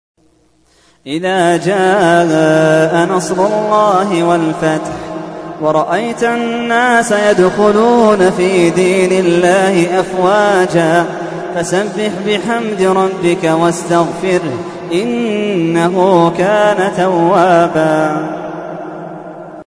تحميل : 110. سورة النصر / القارئ محمد اللحيدان / القرآن الكريم / موقع يا حسين